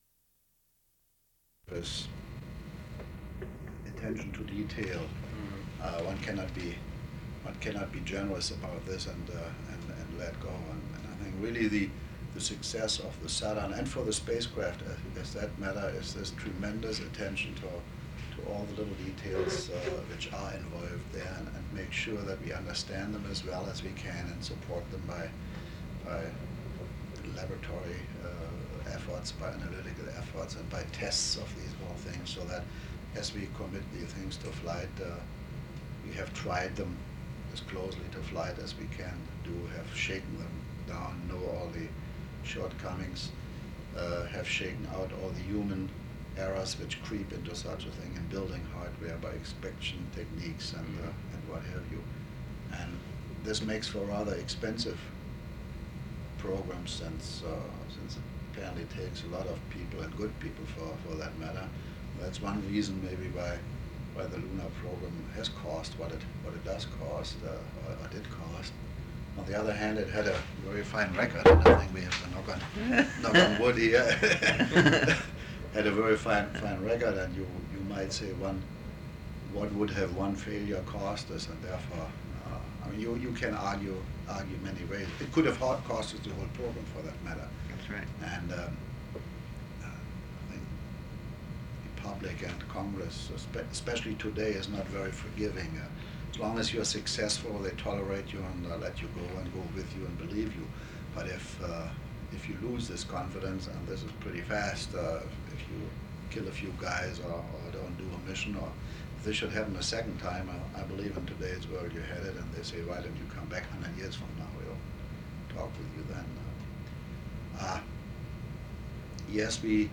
Interviews
Audiocassettes
Relation clir_grant_audio_metadata Oral History Item Type Metadata Duration 0:29:51 Collection Saturn V Collection Tags Oral History Citation United States.